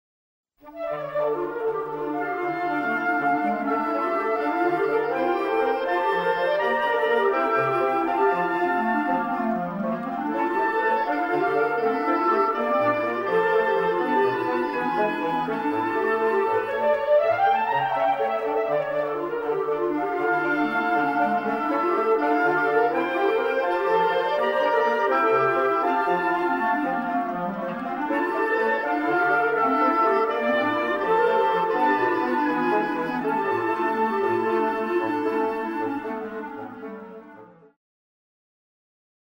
Répertoire pour Chant/vocal/choeur